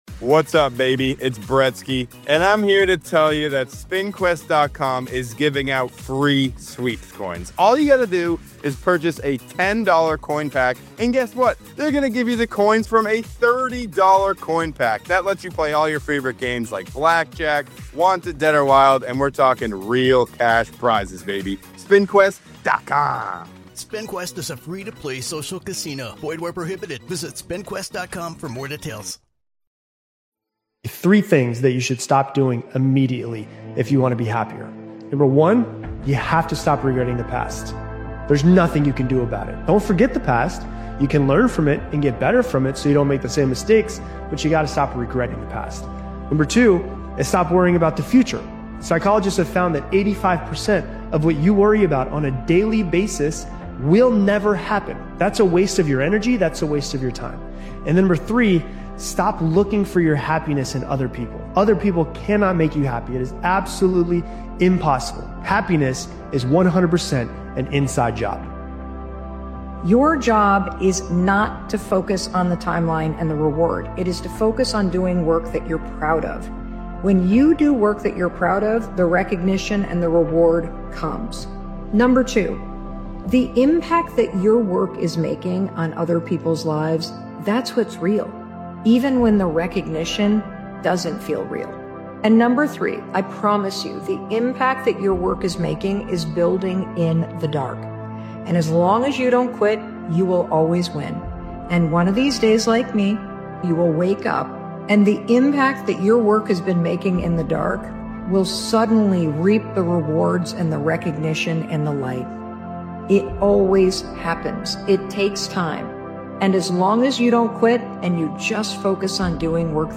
This powerful motivational speech by Daily Motivations is about stepping into the version of you that gets things done. It focuses on discipline, consistency, confidence, and the mindset required to stop hesitating and start executing.